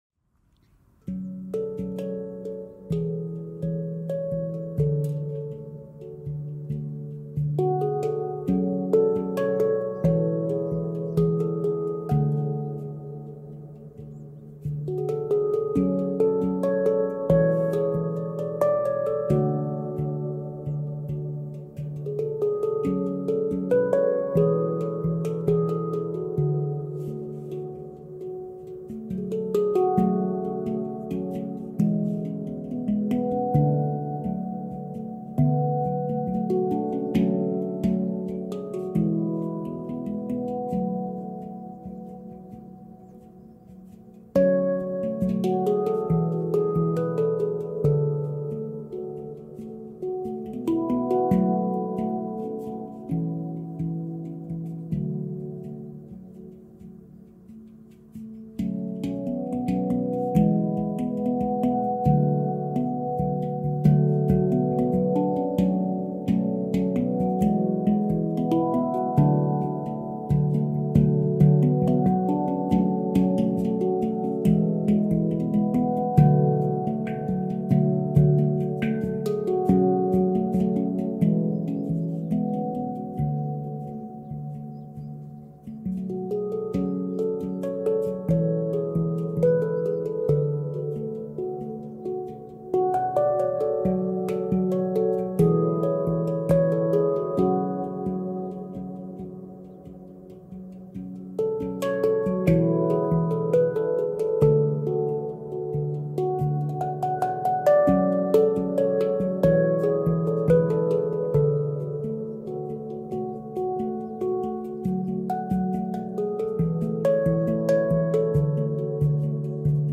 handpan-short.mp3